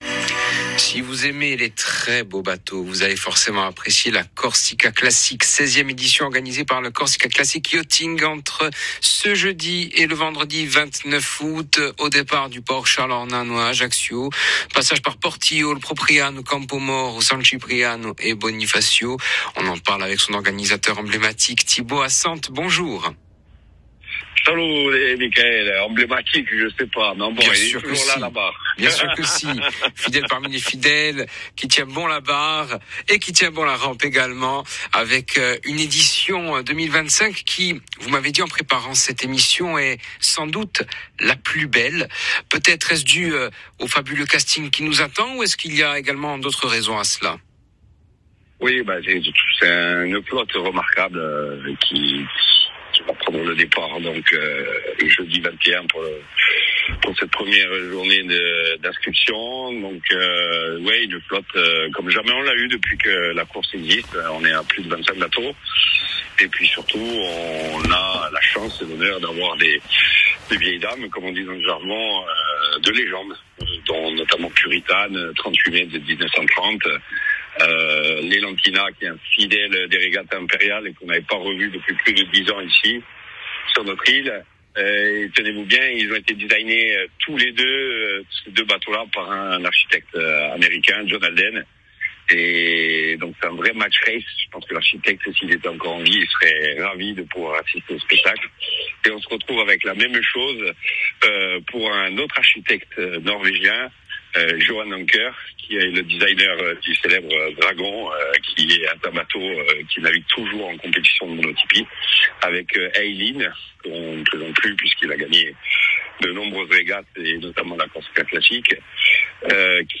en direct